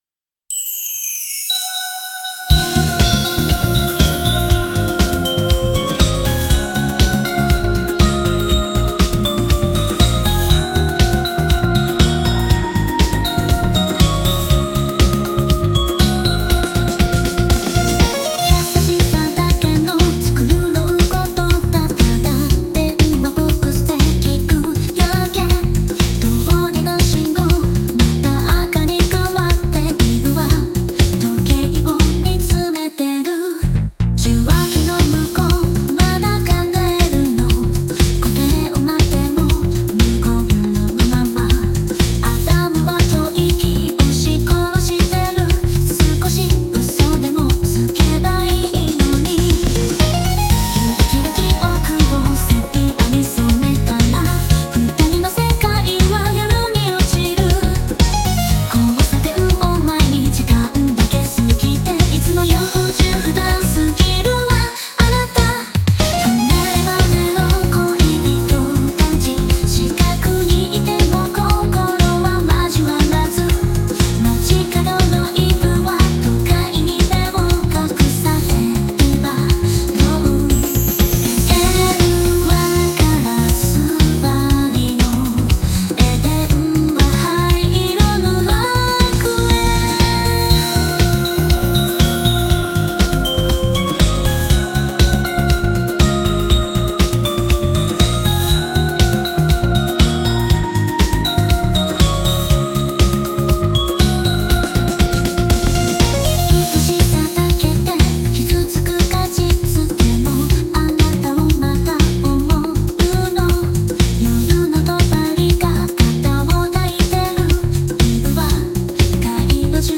AI生成 懐メロ音楽集